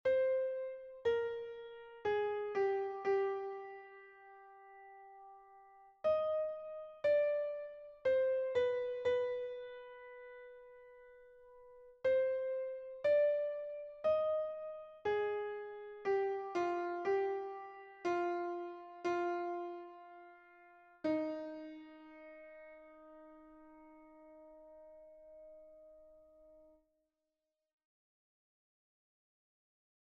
Version piano
Soprano Mp 3